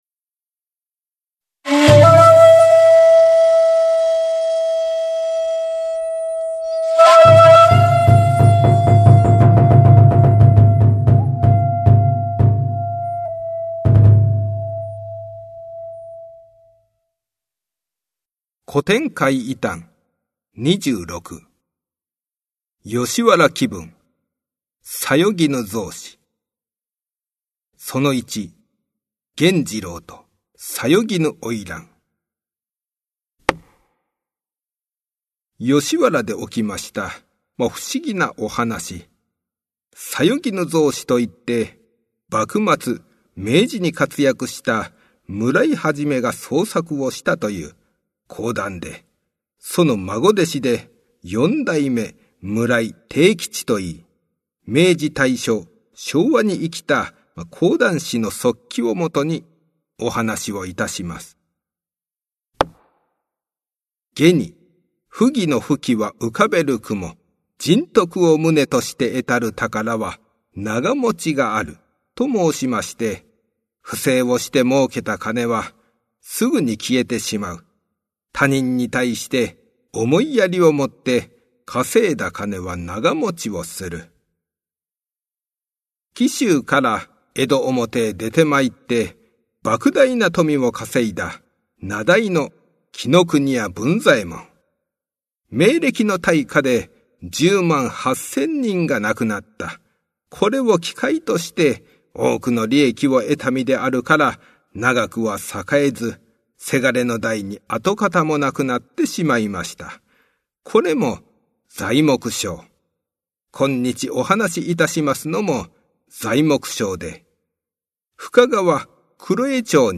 近年、注目を浴びている、日本の伝統話芸「講談」。
名調子で語る「日本の怪談」ここにあり。